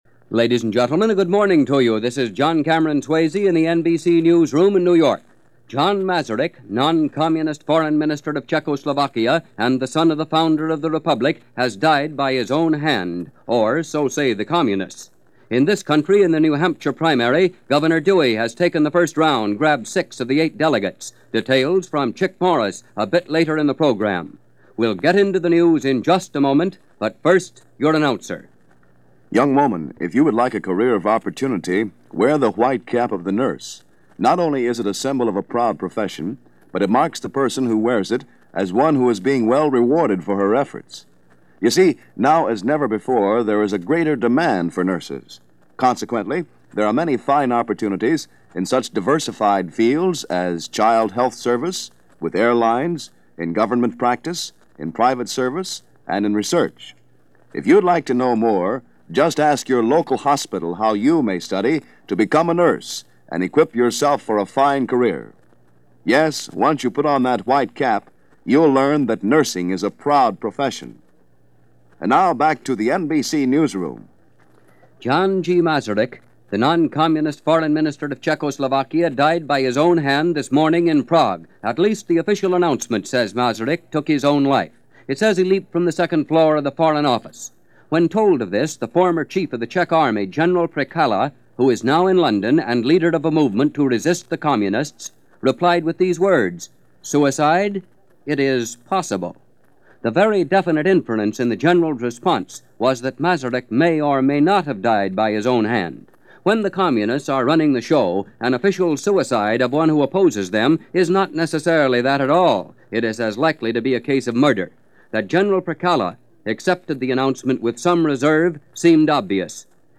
March 10, 1948 - The Death Of Masaryk - Britain's Economic Downturn - Butter Vs. Margarine - news for this day in 1948 from John Cameron Swayze